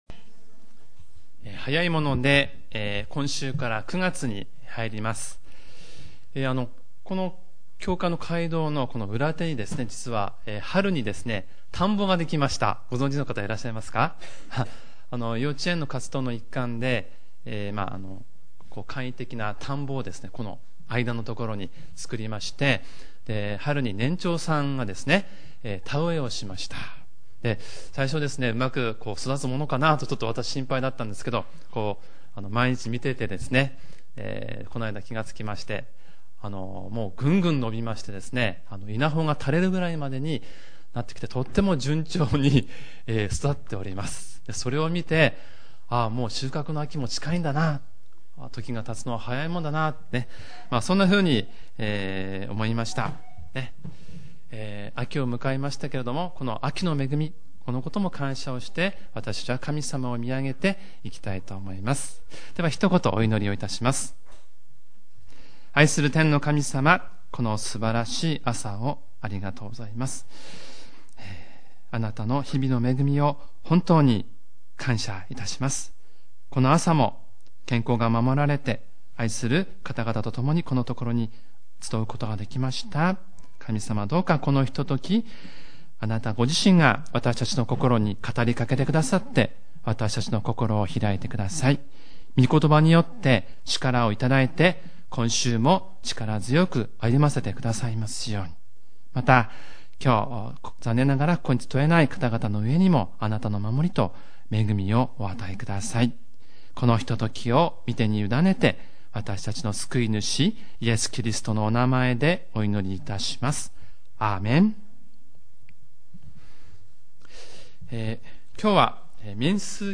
主日礼拝メッセージ